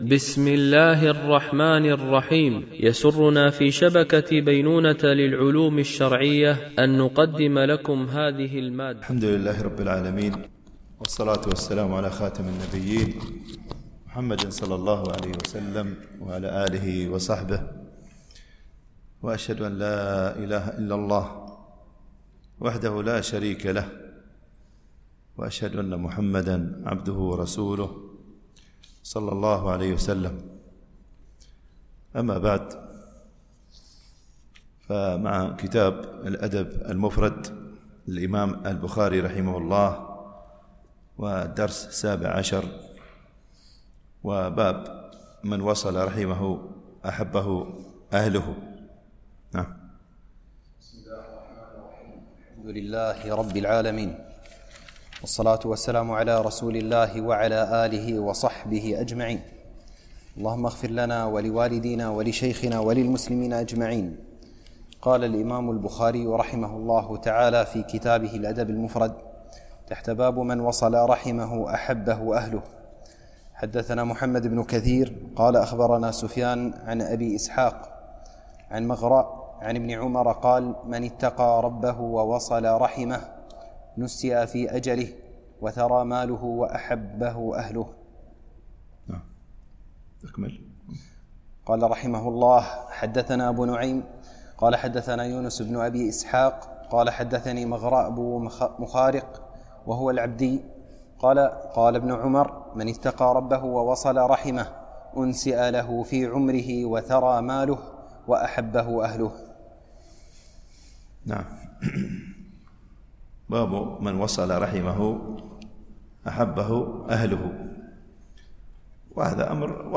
الشرح الثاني للأدب المفرد للبخاري - الدرس 17 ( الحديث 58 -60 )